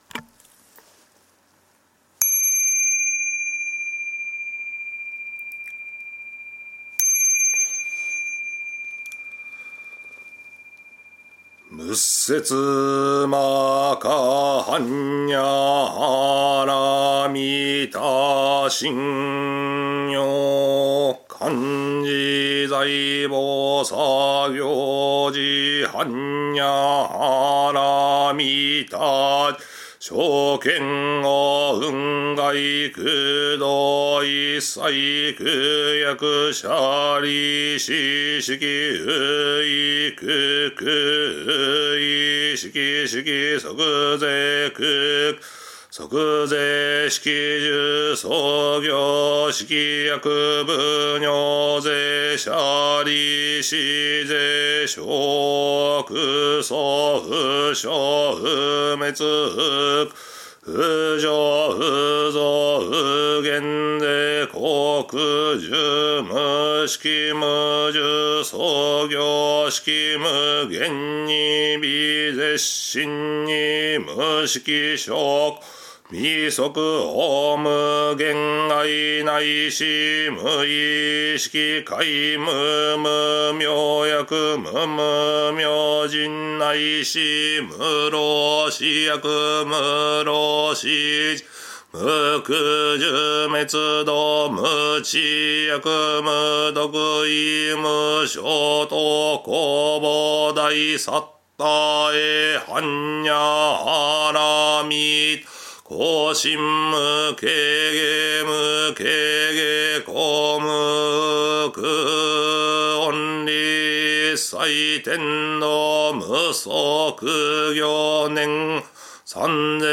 お経は、頭で覚えて読むものではなく、経本を読みながら一字一句間違えが唱えるのがお経ですが、節回し等、各寺院・僧侶によって異なりますのでご注意ください。